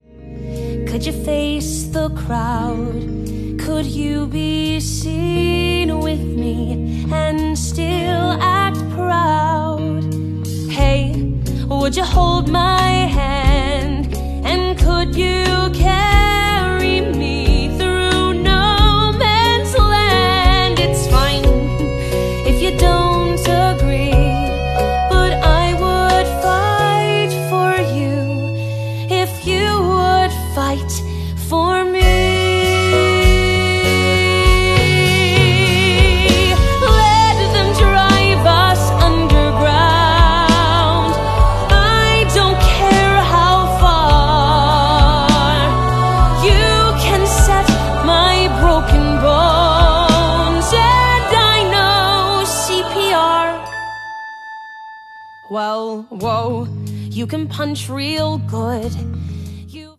Ensemble vocals